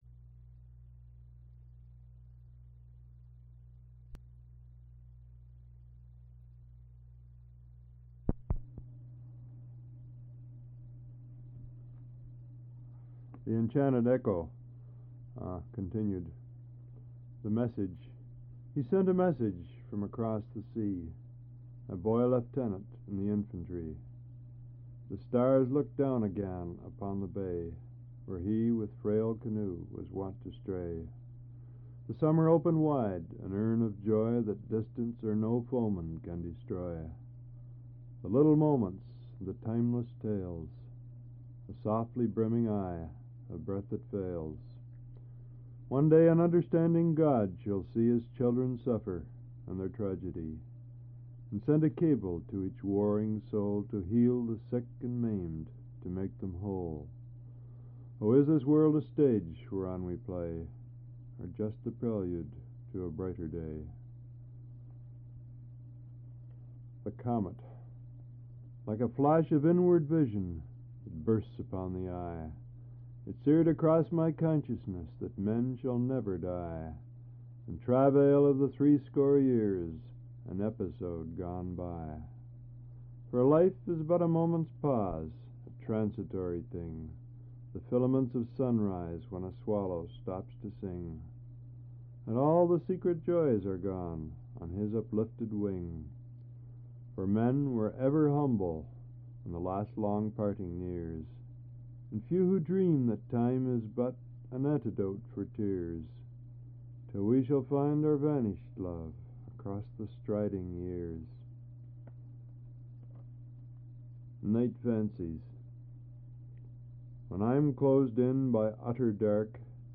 Al Purdy reads his poetry